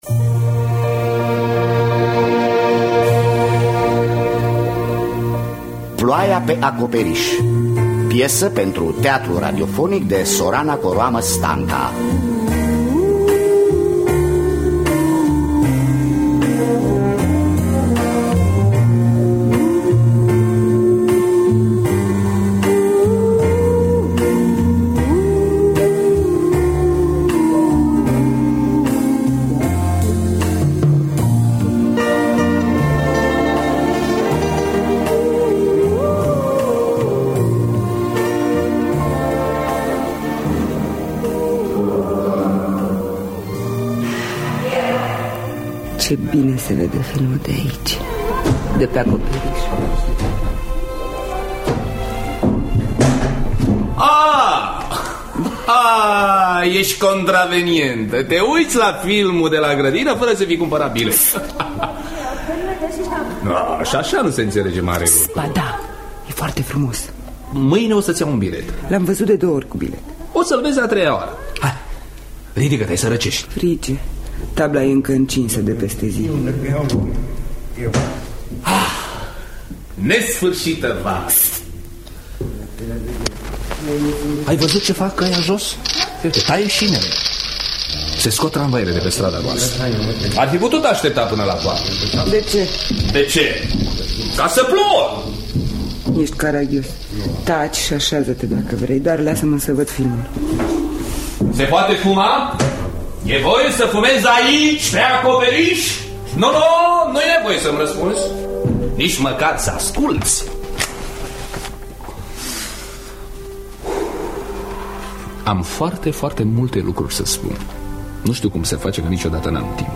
„Ploaia pe acoperiş” de Sorana Coroamă Stanca – Teatru Radiofonic Online
În rolurile principale: Olga Delia Mateescu, Ovidiu Iuliu Moldovan.